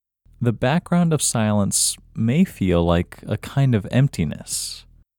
LOCATE Short OUT English Male 9